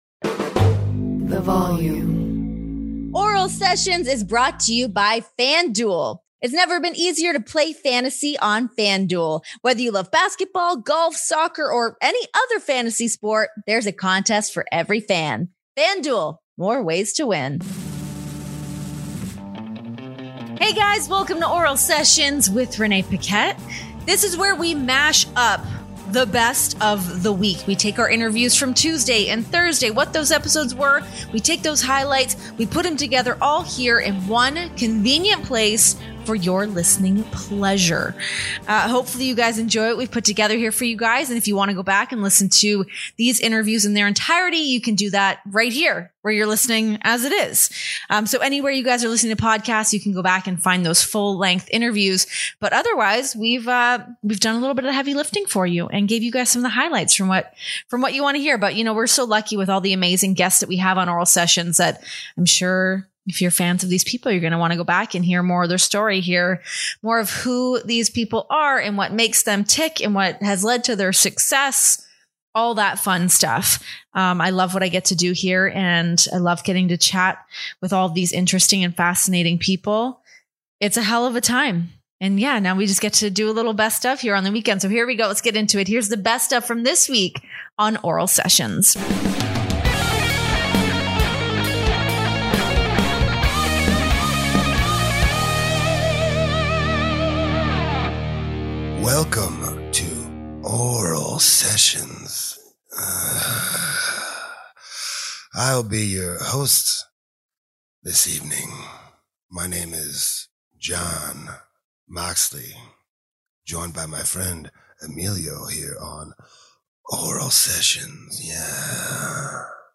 Relive the best of Oral Sessions this week, featuring highlights from our interviews with Trish Stratus and the baby daddy Jon Moxley.